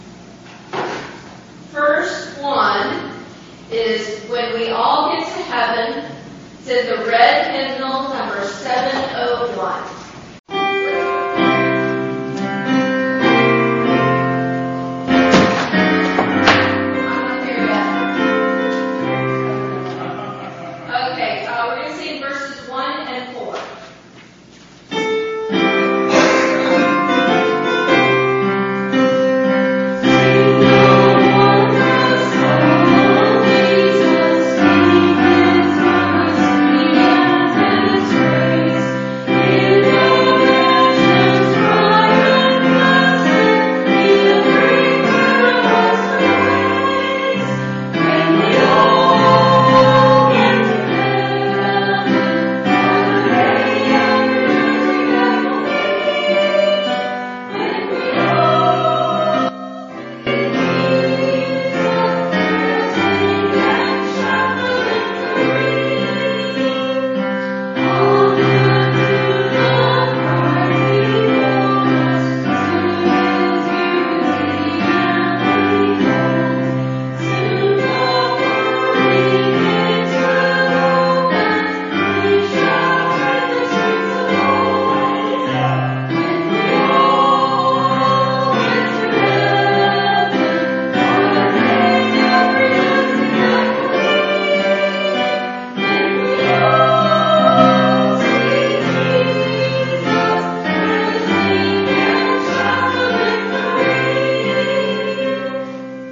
Hymn Sing
It has been said that each hymn contains a sermon/message, so consider taking some time to read all of the verses to reveal the full message of the hymn...Each recording includes statements made before the hymn is sung...as well as any comments made after the singing of the preceding hymn.